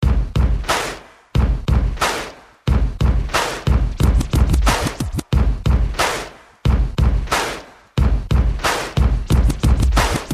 drum-beats-and-claps_24798.mp3